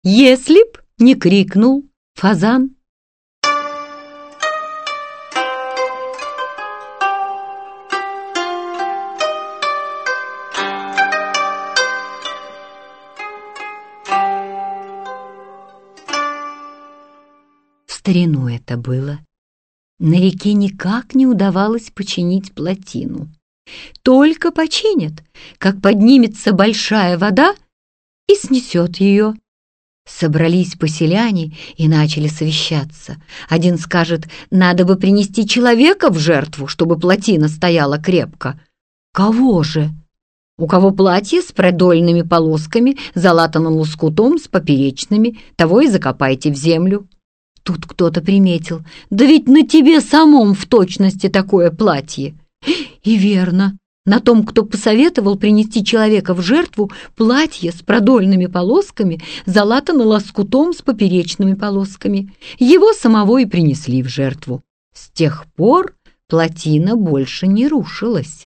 Аудиокнига Японские сказки. Счастливые несчастья | Библиотека аудиокниг
Прослушать и бесплатно скачать фрагмент аудиокниги